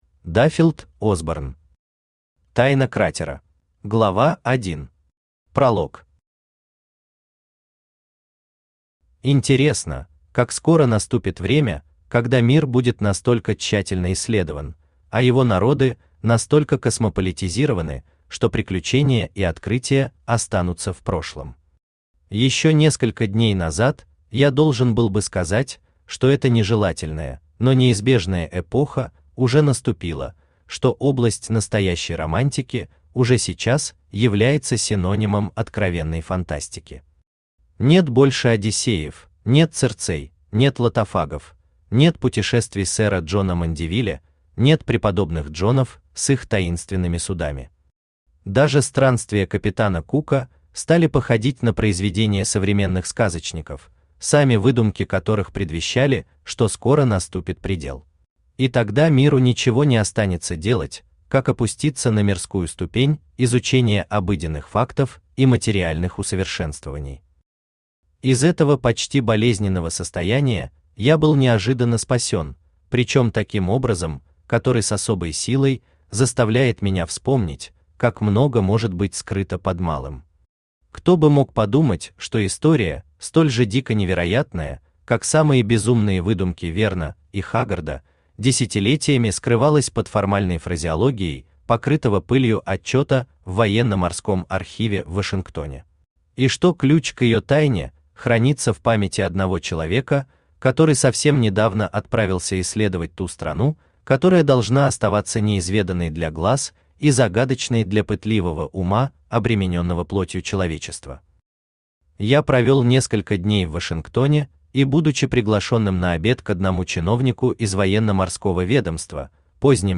Аудиокнига Тайна кратера | Библиотека аудиокниг
Aудиокнига Тайна кратера Автор Даффилд Осборн Читает аудиокнигу Авточтец ЛитРес.